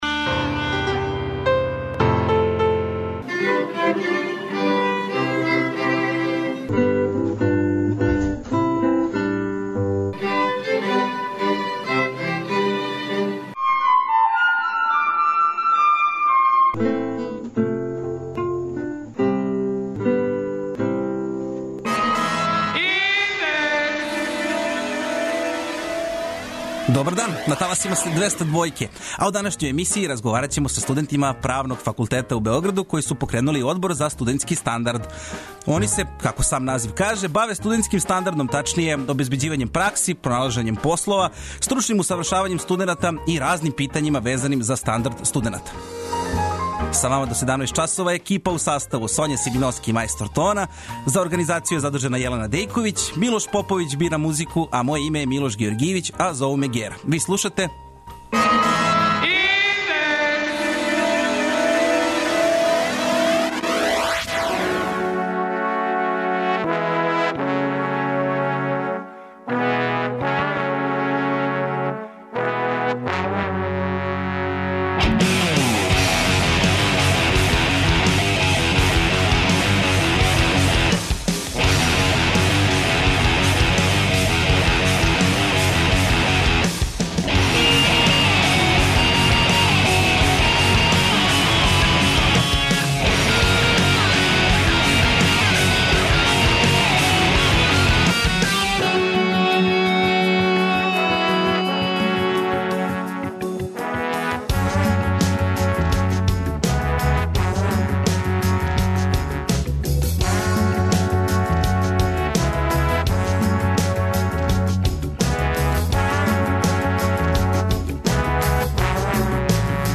У данашњој емисији послушајте разговор са студентима Правног факултета у Београду који су покренули Одбор за студентски стандард.